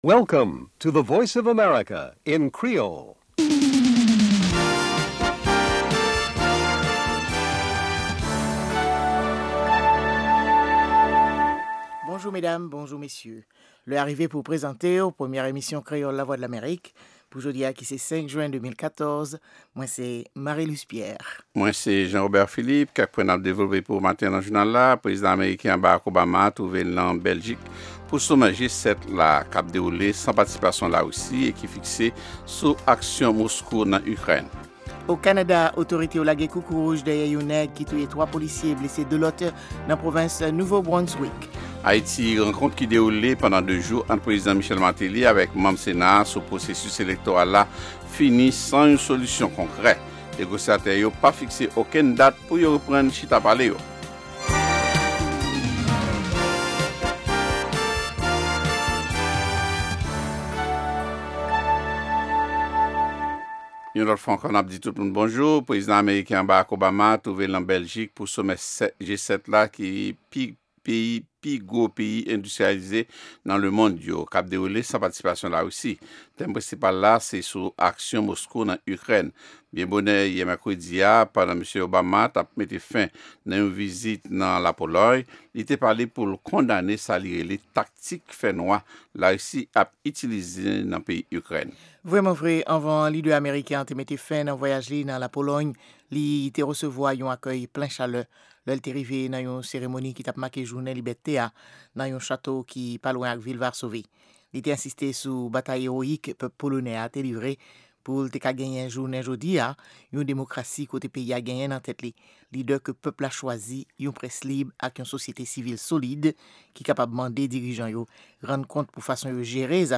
Se premye pwogram jounen an ki gen ladan dènye nouvèl sou Lèzetazini, Ayiti ak rès mond la. Pami segman yo genyen espò, dyasporama ak editoryal la.